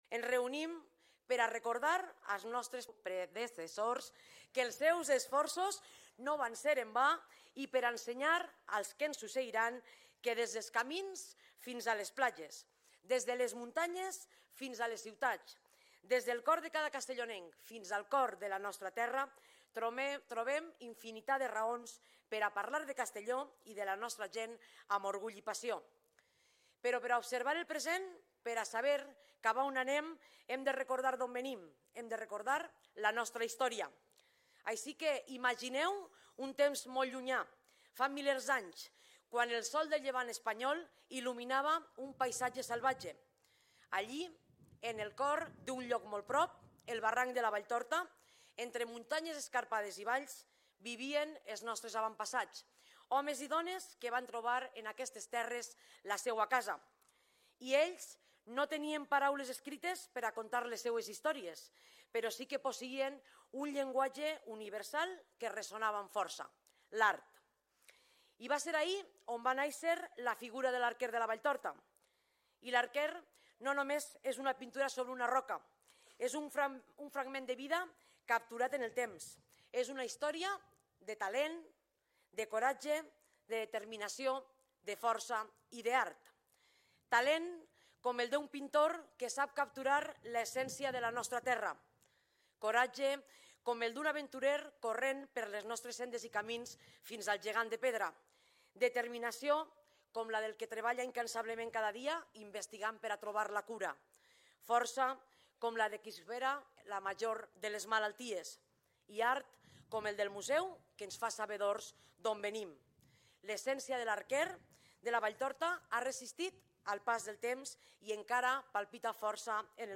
Presidenta-Marta-Barrachina-Dia-de-la-Provincia-1.mp3